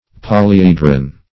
Polyedron \Pol`y*e"dron\, n.
polyedron.mp3